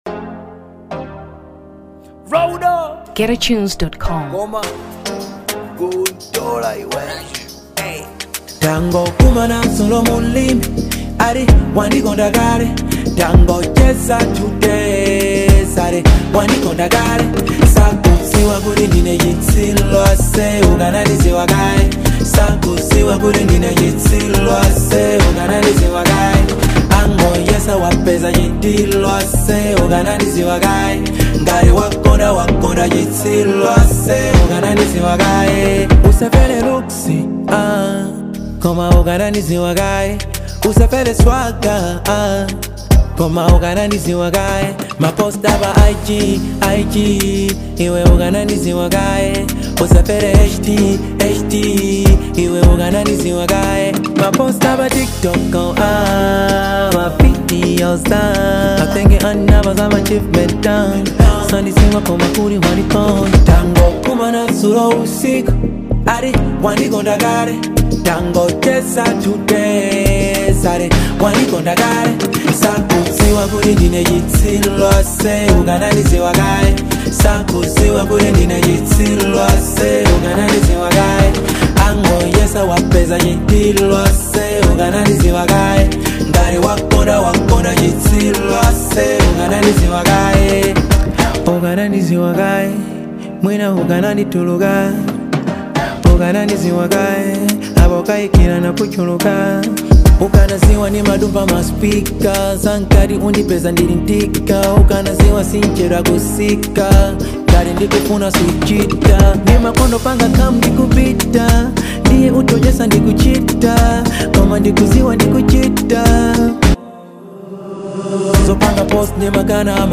Afrobeats 2023 Malawi